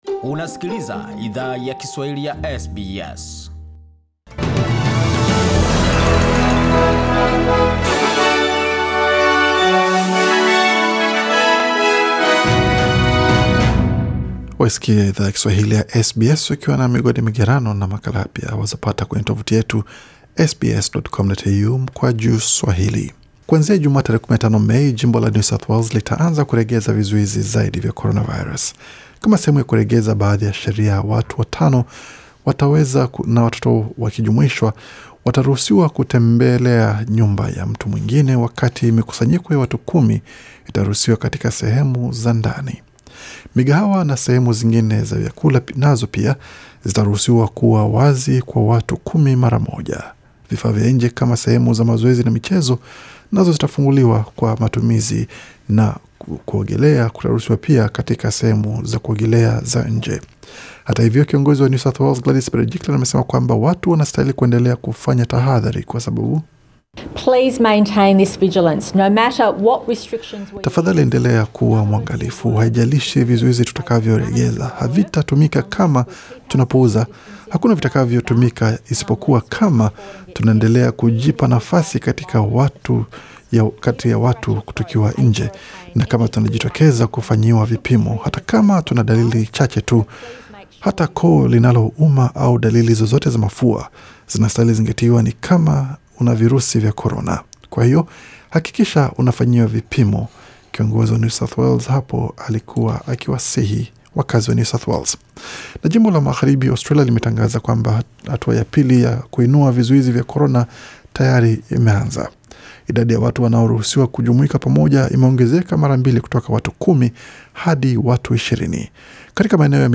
Taarifa za habari: Majimbo nchini Australia yaanza kuondoa vizuizi vya coronavirus